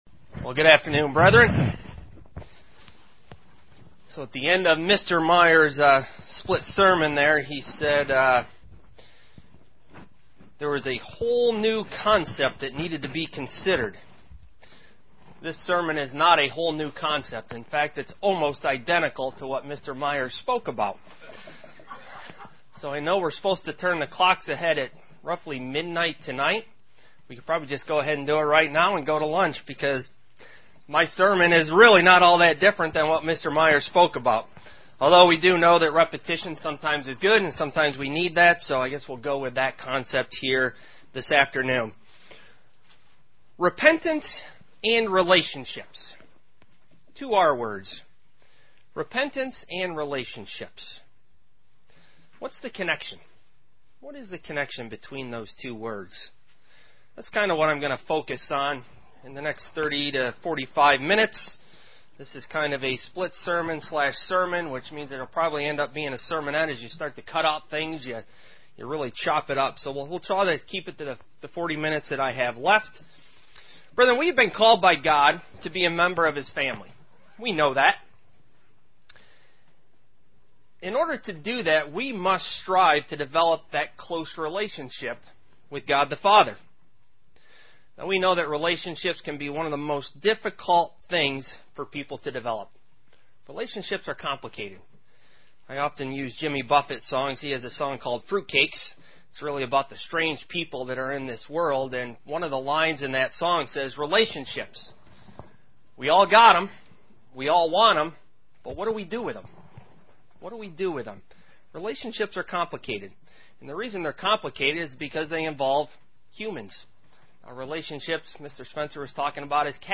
Sermons
Given in Elmira, NY